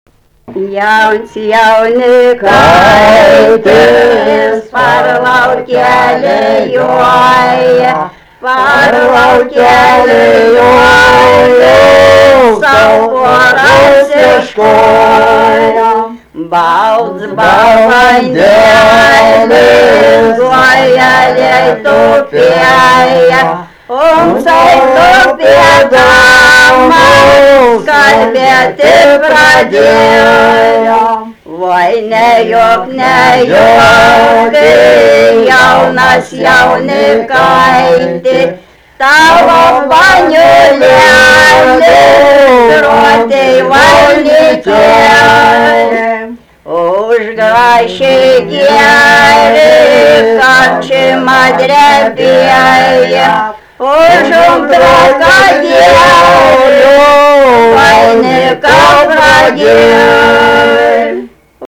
daina
vokalinis
2-3 balsai